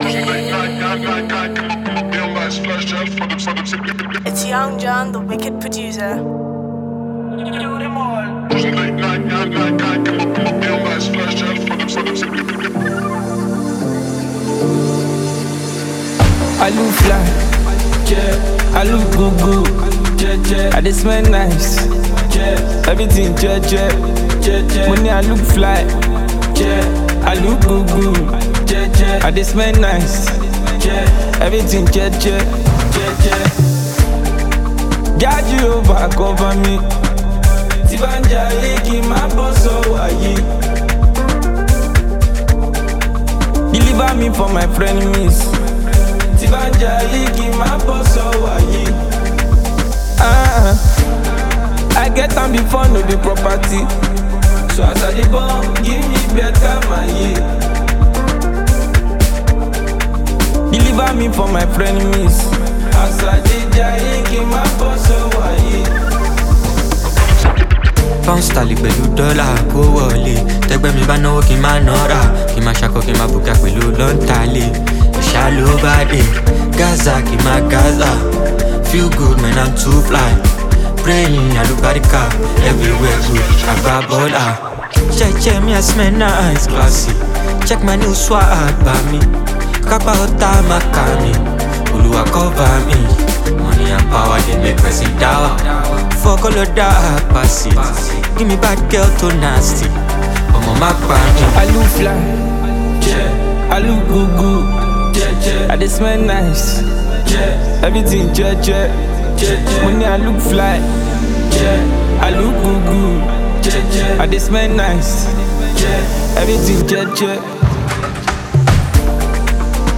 feel-good, replay-worthy Afropop